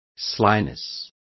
Complete with pronunciation of the translation of slyness.